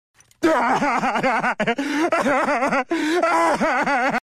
Peter Griffin Crying - Family Guy